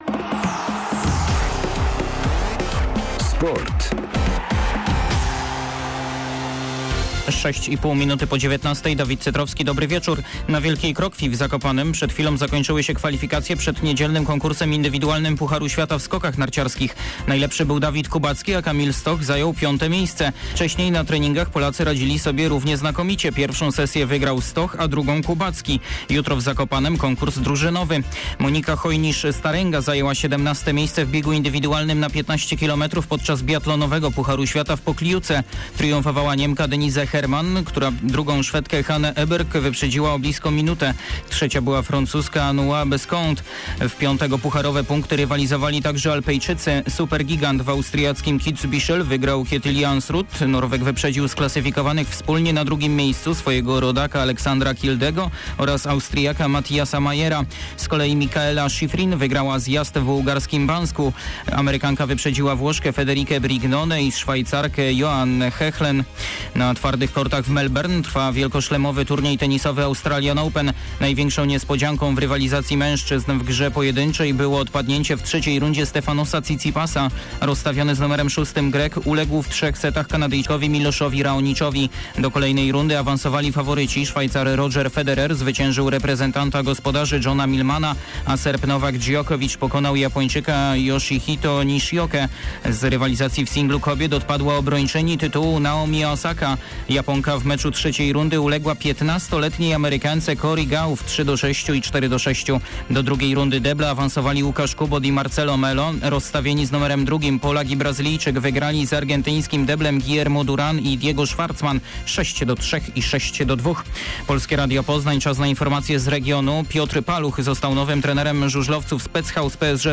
24.01. SERWIS SPORTOWY GODZ. 19:05